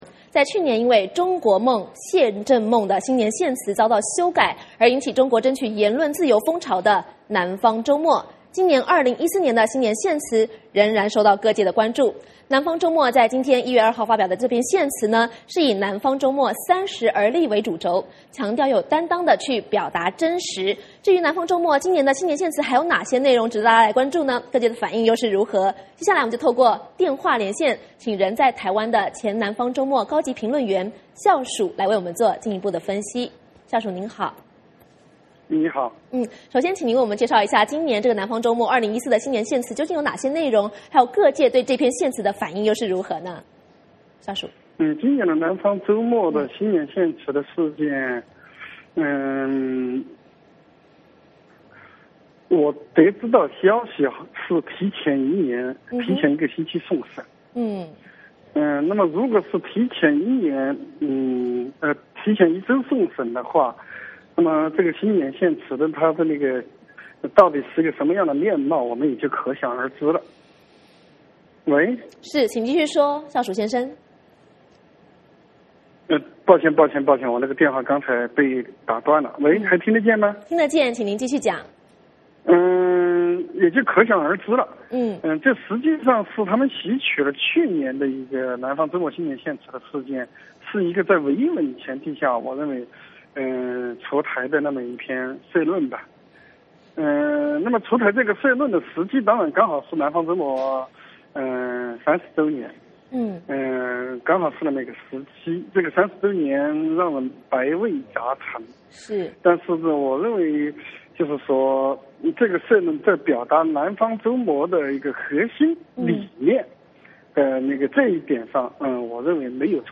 我们透过电话连线